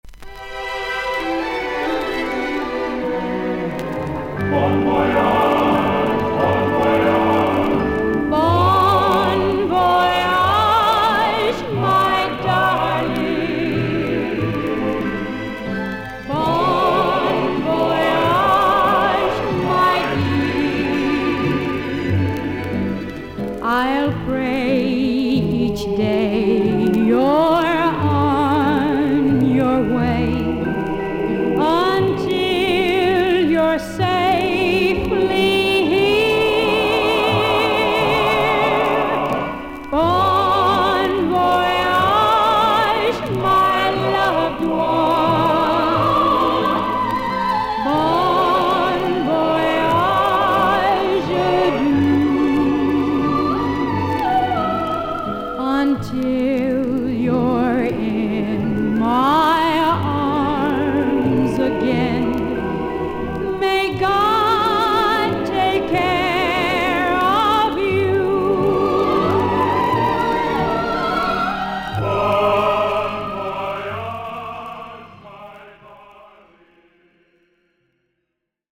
透明感とあたたかみのある歌声の女性シンガー。
VG++〜VG+ 少々軽いパチノイズの箇所あり。クリアな音です。